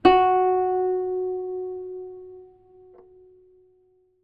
ZITHER F#2.wav